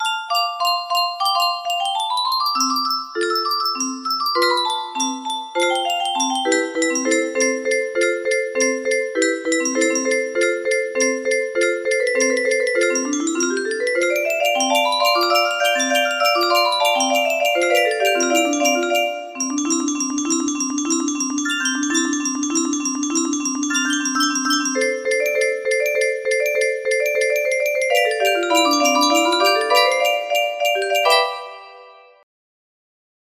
de oma scheter-beat music box melody